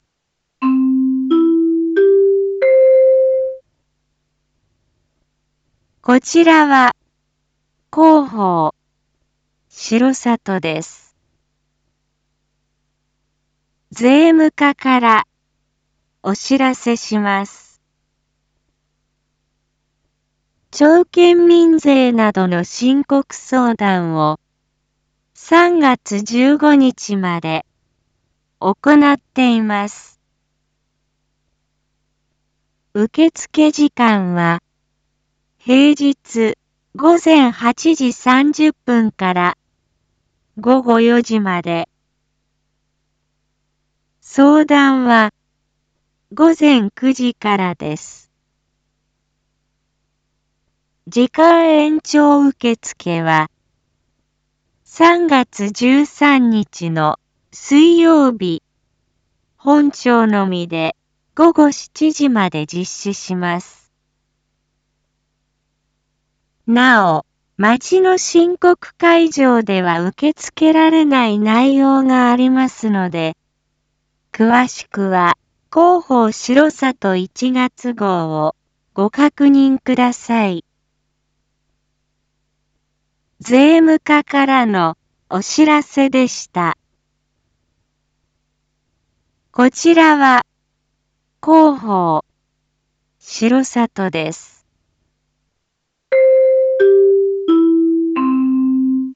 Back Home 一般放送情報 音声放送 再生 一般放送情報 登録日時：2024-03-08 07:01:40 タイトル：申告相談D① インフォメーション：こちらは広報しろさとです。